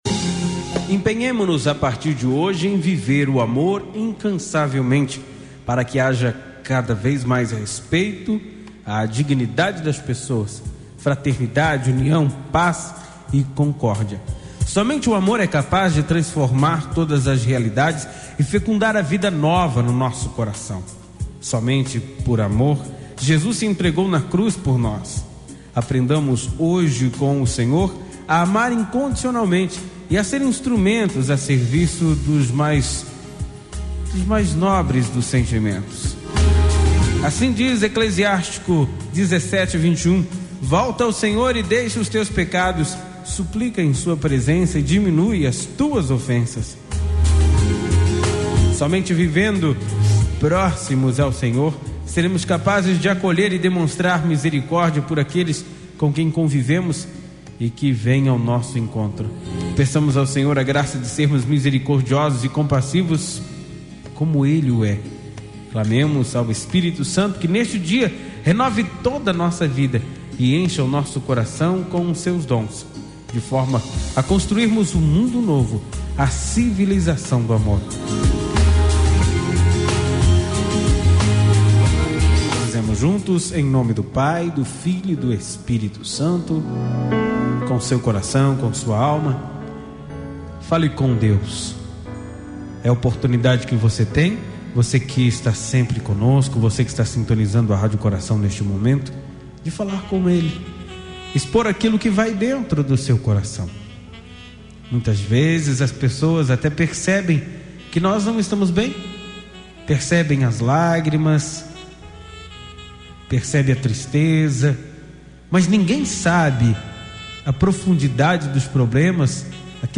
Oração desta manhã